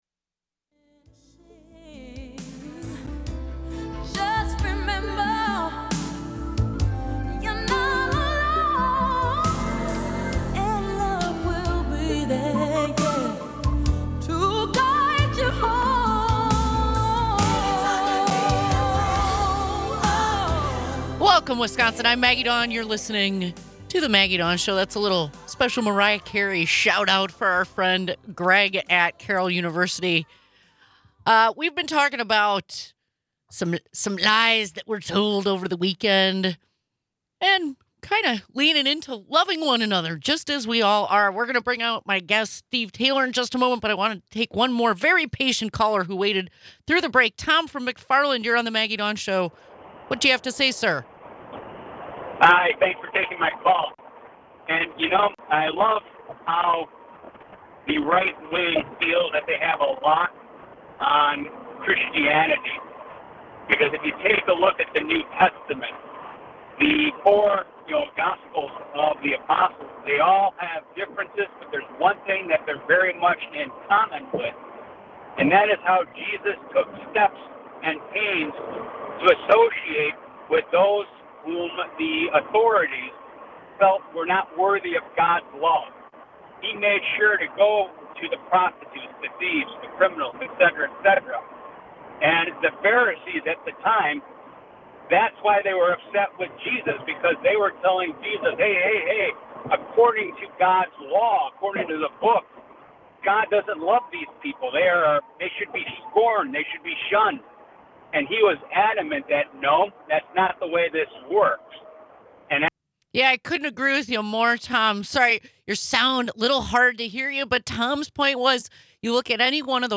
The episode critically examines the ritualistic laying of hands and prayers over Trump at his rallies, juxtaposed with his own ambiguous statements about his religious beliefs and practices. The episode features an engaging conversation with conservative friend and former Milwaukee County Supervisor Steve Taylor, offering a rare glimpse into the bipartisan dialogue in today's polarized world.